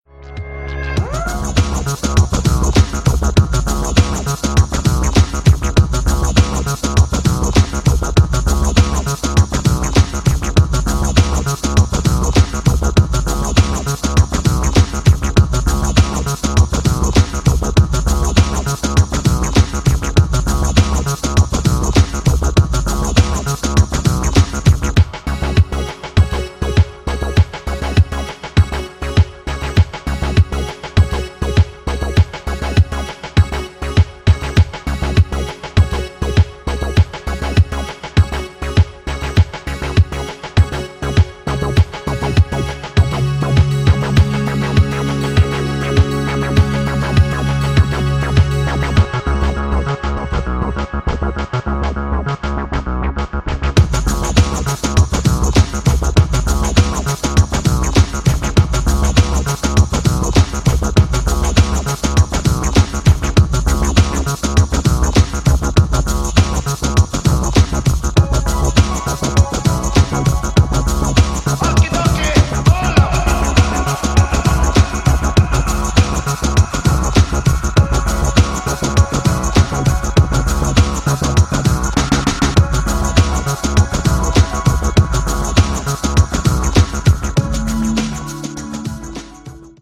ジャンル(スタイル) HOUSE / BALEARIC